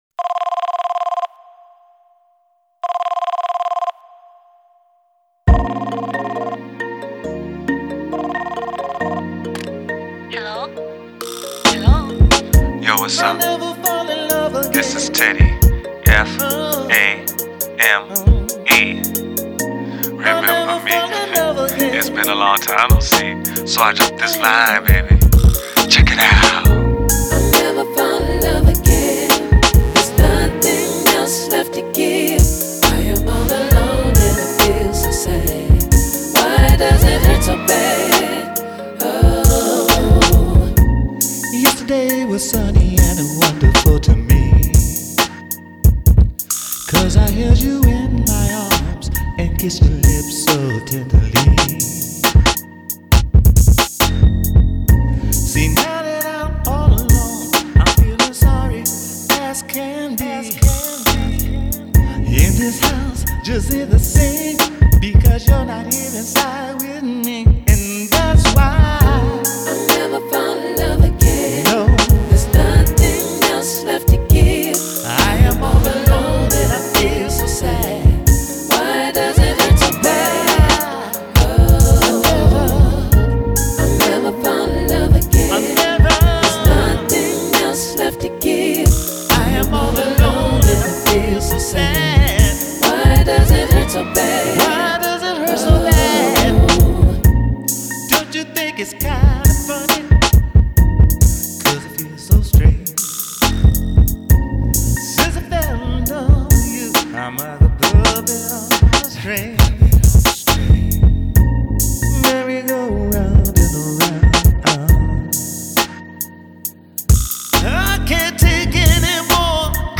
Home > Music > Rnb > Bright > Smooth > Medium